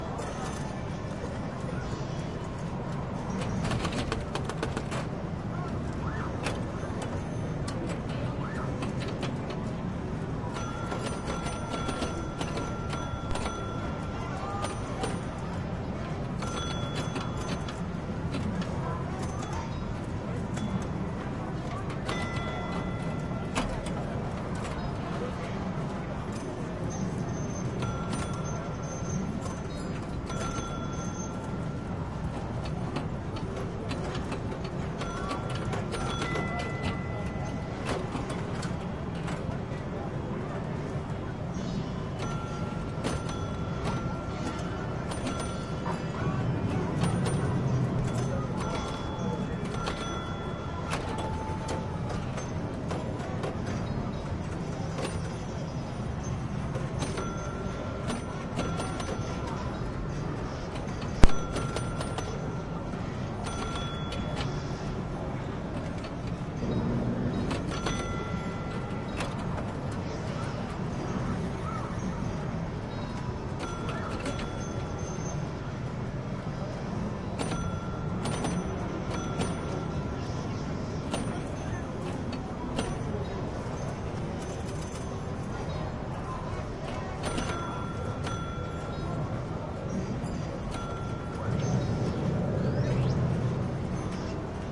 经典弹球游戏玩法
描述：这是一款经典的1970年代弹球机（Quick Draw）的短剧游戏。
标签： 快速 脚蹼 游戏 弹珠台
声道立体声